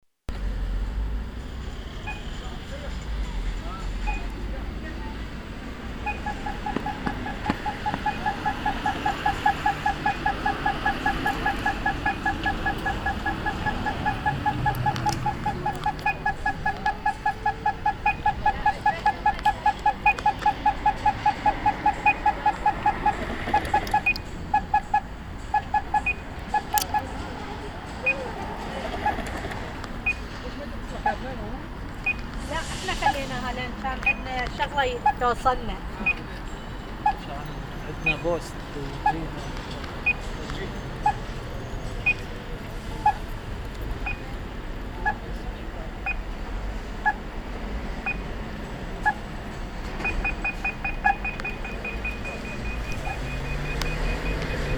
Hörbeispiele: nach Aktivierung durch Funkhandsender:
Hörbeispiel Bulgariplatz (Hier sind 2 Anmeldetableaus auf einem Ampelmast montiert (Um 90 Grad versetzt): :